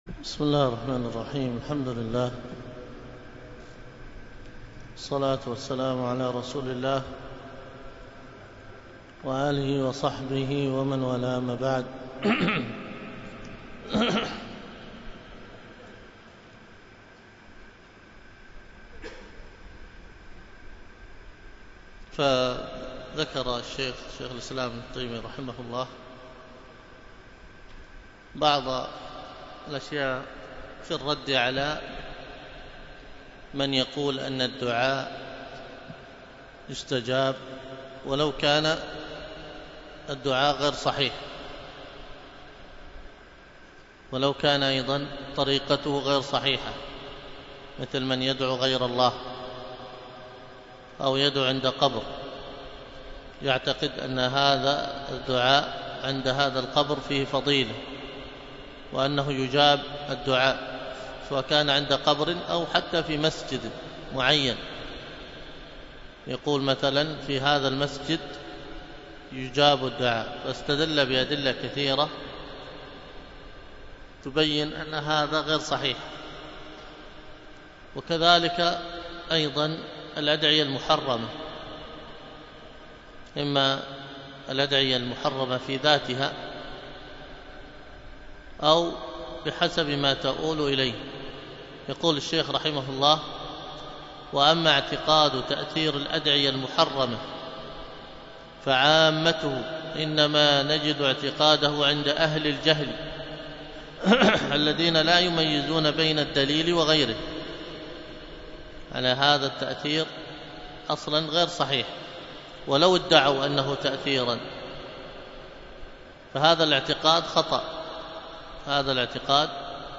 الدرس في آداب المساجد 4